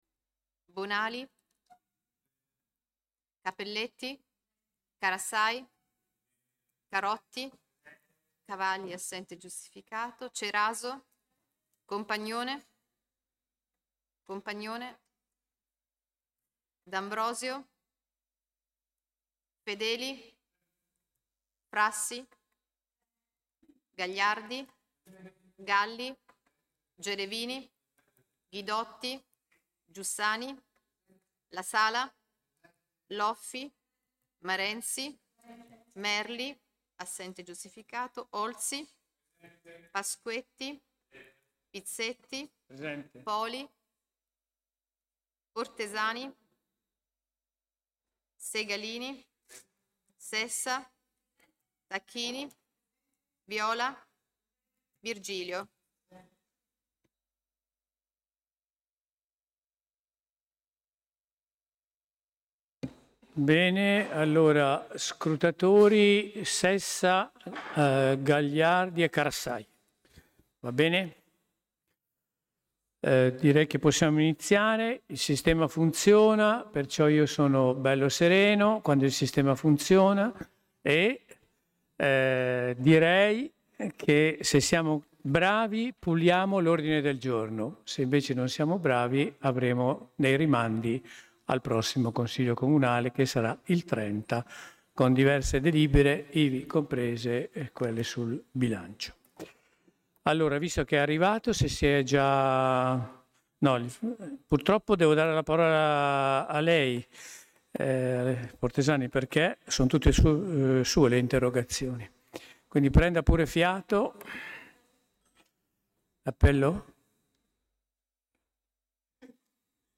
Seduta di Consiglio del 13/04/2026 [1]